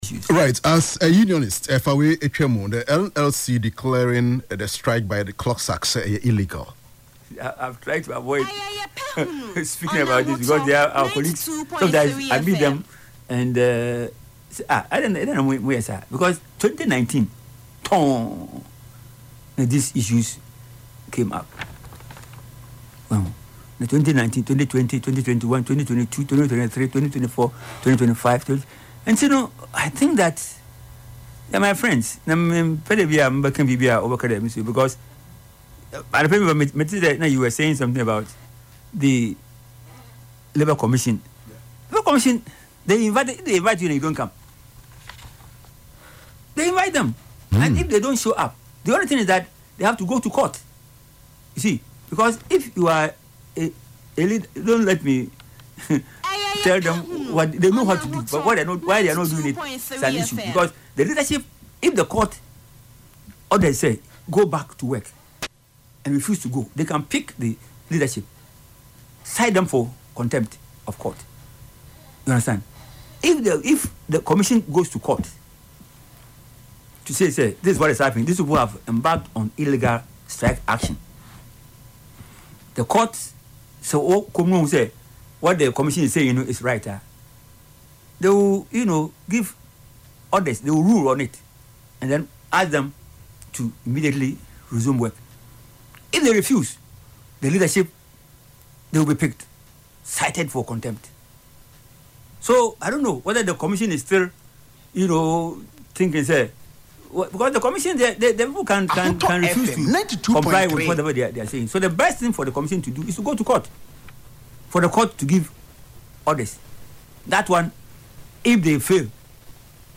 Reacting to the development on Ahotor FM’s “Yepe Ahunu” show on Saturday, March 14, 2026,